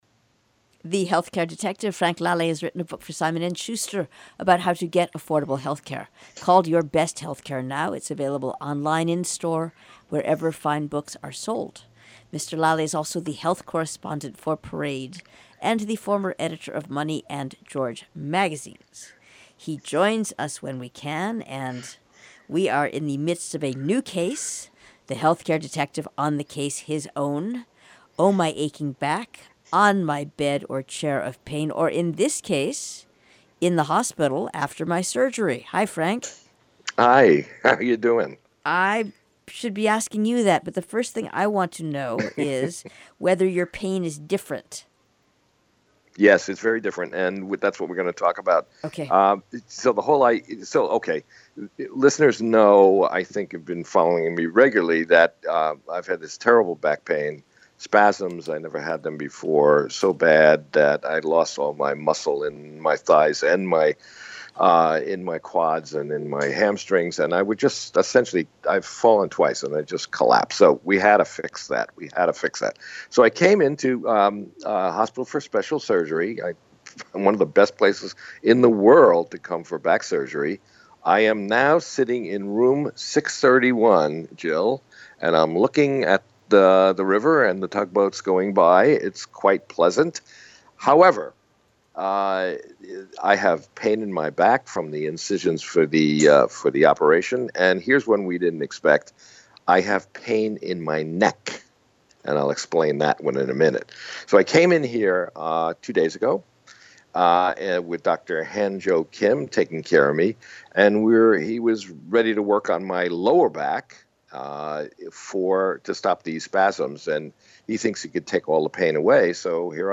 Back Pain Update 5, Live From the Hospital for Special Surgery